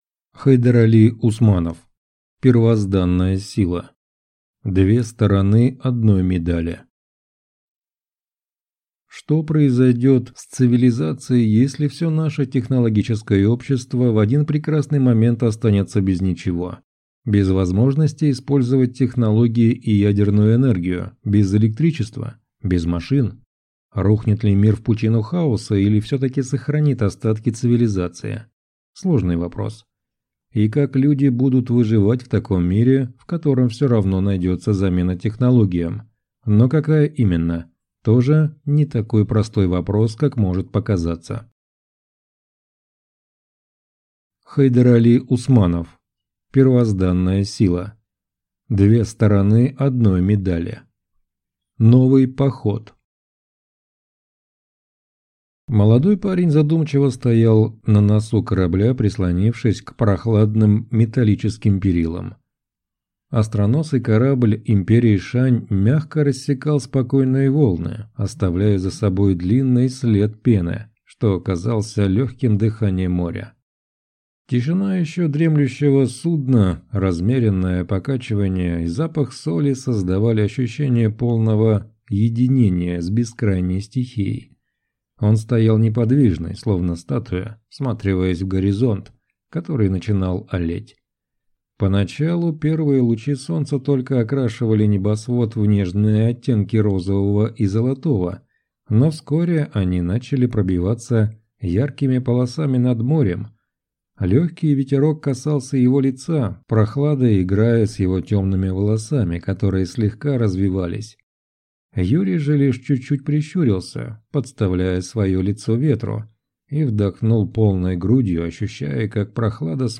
Первозданная сила. Две стороны одной медали (слушать аудиокнигу бесплатно) - автор Хайдарали Усманов